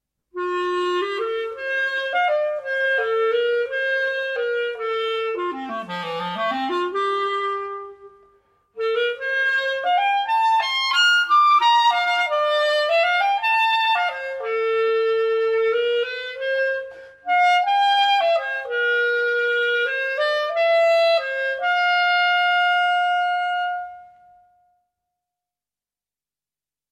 CLARINETE.mp3